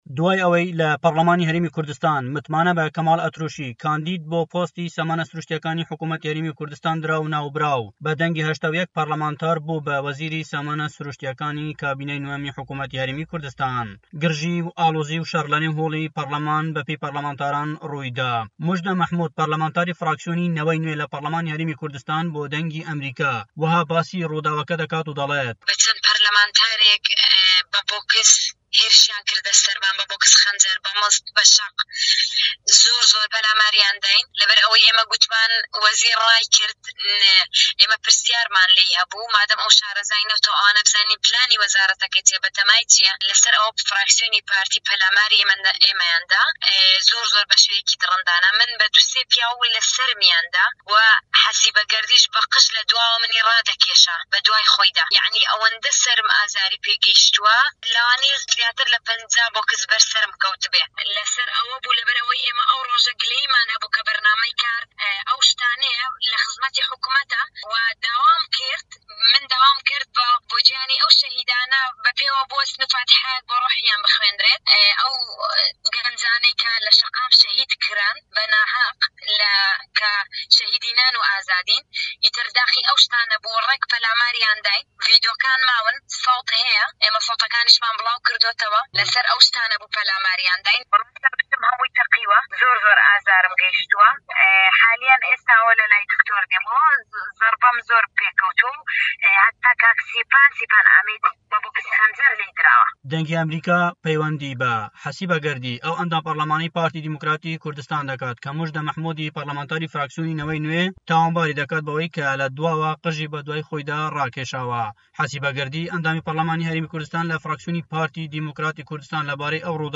لای خۆیەوە دکتۆر ڕێواس فایەق سەرۆکی پەرلەمانی هەرێمی کوردستان لە پرێس کۆنفرانسێکدا دەڵێت" کۆی ڕووداوەکە مایەی نیگەرانی هەموومانە و داوای لێبوردن لە هەموو ئەوانە دەکەم کە زیانیان بەرکەوتووە لەو پرۆسە و کردەوەیە، دەمەوێ یەک حەقیقەت بۆ ڕای گشتی بڵێم هەموو جارێک وەکو سەرۆکی پەرلەمان تەحەمولی ئەو هەموو زوڵمە دەکەم کە لێم دەکرێت لەکاتێکدا دەڵێن سەرۆکی پەرلەمان ناهێڵێ قسە بکات من وێنە گەورەکەی ناو هۆڵی پەرلەمان دەبینم ئەزانم ئەگەر دەرفەت بدەم هەموو ڕۆژی ئەو جۆرە شەڕە ڕوودەدات، هەفتەی داهاتوو سەرۆک فڕاکسیۆنەکان کۆدەکەینەوە دەبێت لەسەر میکانزمێک ڕێکبکەوین بۆ کەمکردنەوەی گرژیانەی ناو پەرلەمان ڕوودەدات."